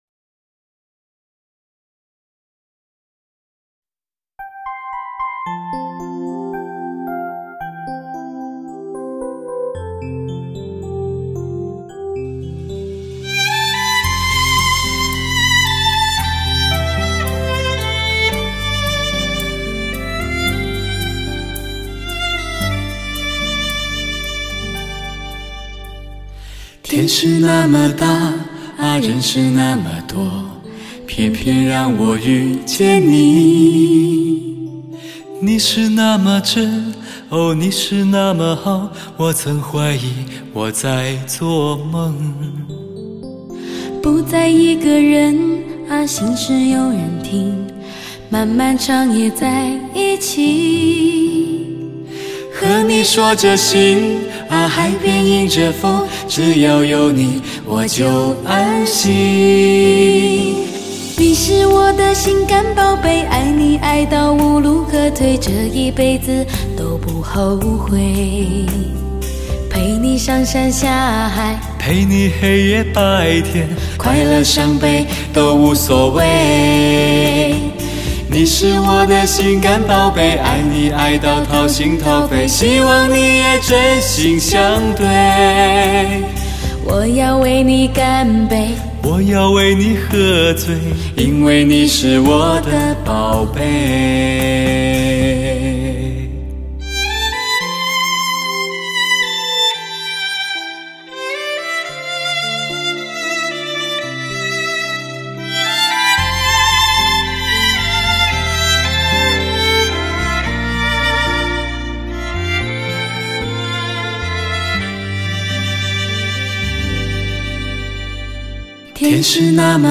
类型: 天籁人声
最美的女声真诚献唱，情感丰富的嗓音重新诠释十三首乐韵柔扬歌曲，精彩过瘾，独特又美妙的女声代表作！